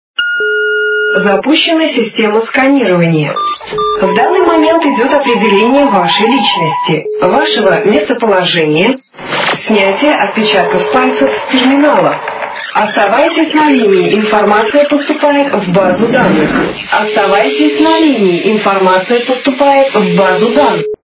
» Звуки » Люди фразы » Голос - Звонок секретной службы (запущена система сканирования)
При прослушивании Голос - Звонок секретной службы (запущена система сканирования) качество понижено и присутствуют гудки.
Звук Голос - Звонок секретной службы (запущена система сканирования)